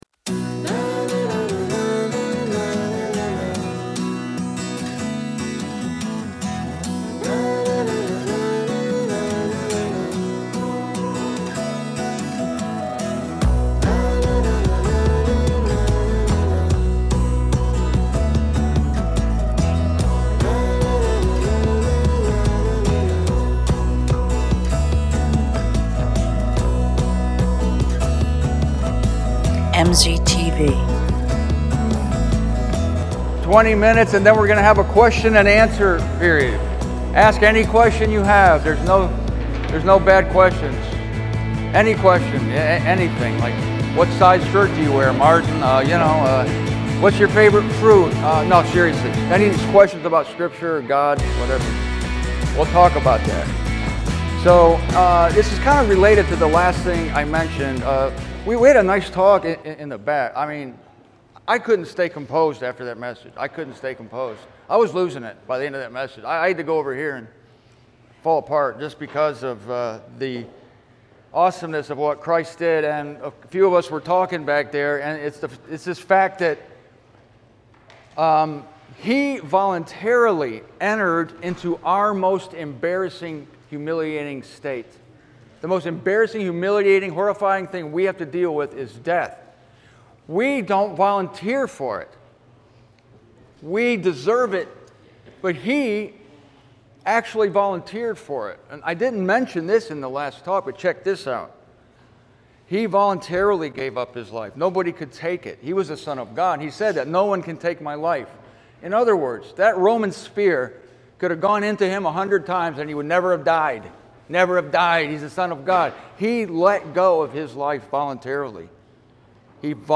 Also, join a Q&A session that includes Q's you've never heard and A's that will blow your mind.